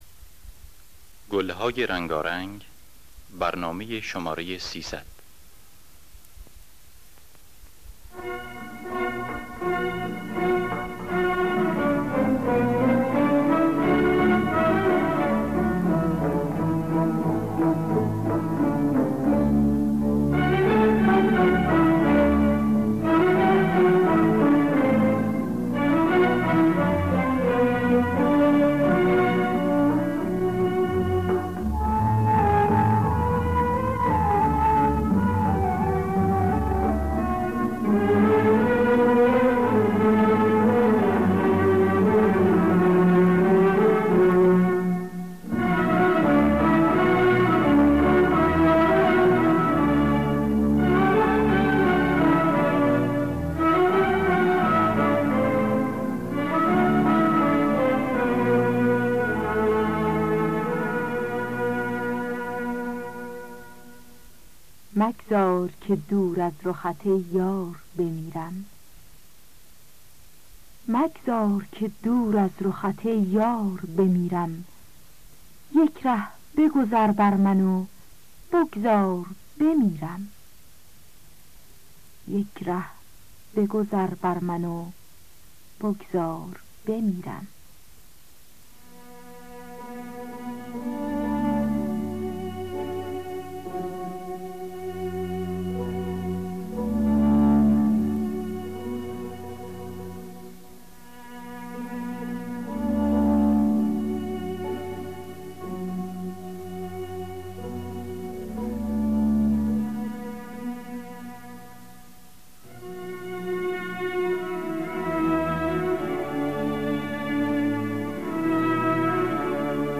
دانلود گلهای رنگارنگ ۳۰۰ با صدای الهه، عبدالوهاب شهیدی در دستگاه دشتی.
خوانندگان: الهه عبدالوهاب شهیدی نوازندگان: پرویز یاحقی جواد معروفی